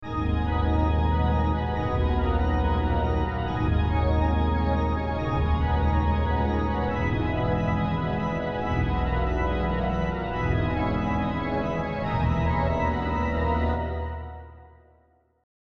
Орган вообще мащща.
organ.mp3